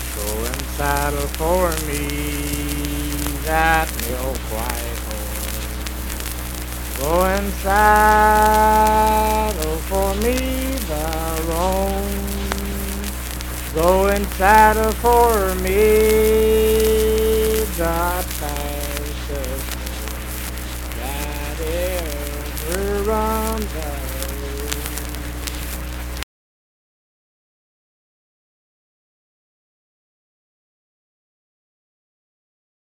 Unaccompanied vocal music
Voice (sung)
Pleasants County (W. Va.), Saint Marys (W. Va.)